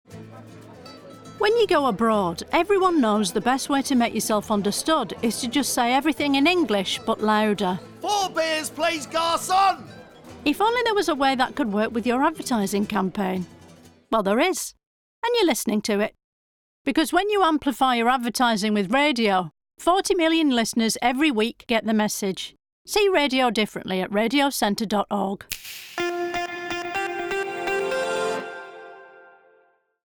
Audio ads, which are voiced by comedian Diane Morgan, were created by Radioville.
From shouting dinner orders abroad to yelling at footballers from the stands, the mix of 30, 20 and 10 second ads comically show how turning up the volume gets you heard – just like great radio advertising.